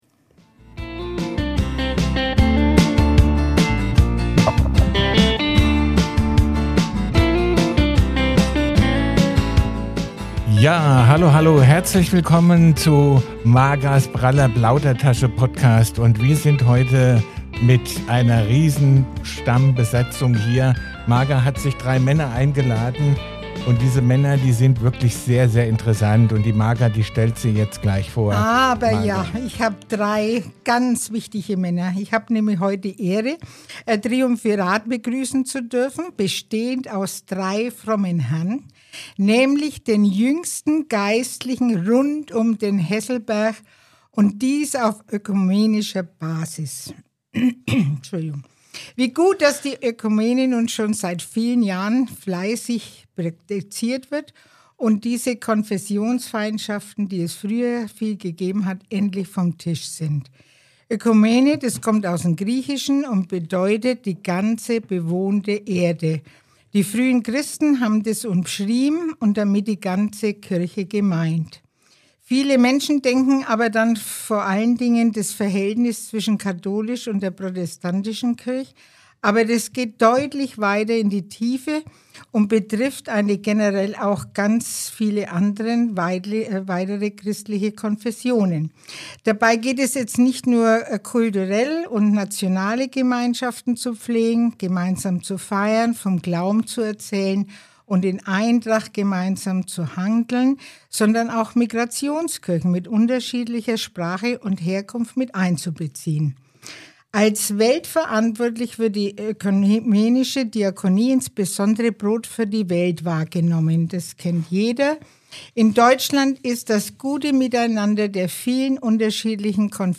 In dieser Folge habe ich drei bemerkenswerte Geistliche zu Gast, die offen und humorvoll über ihre Berufung, familiären Alltag und die Herausforderungen in ihren Gemeinden sprechen.